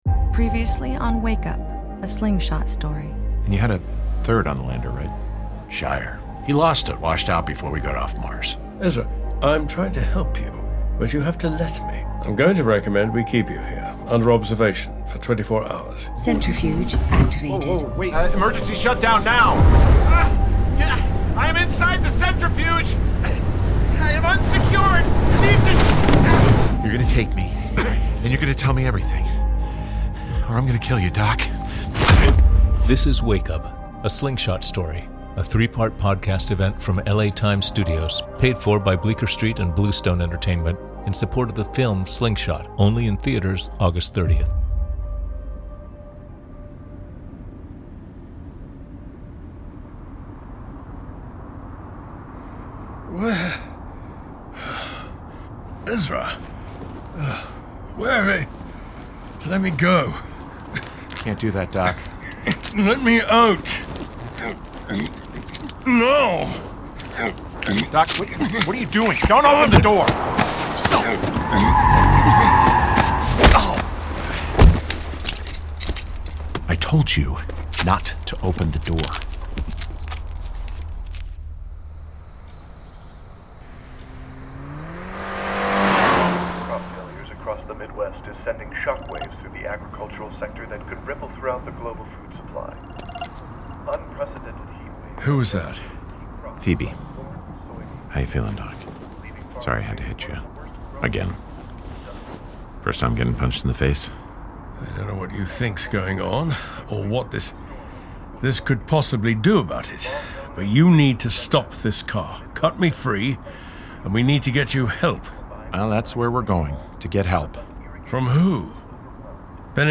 … continue reading 4 episodes # Cinema # Audio Drama # L.A. Times Studios # Sci-Fi / Fantasy Stories # Thriller # Slingshot # Science Fiction # Bleecker St # LA Times # Scifi # Wake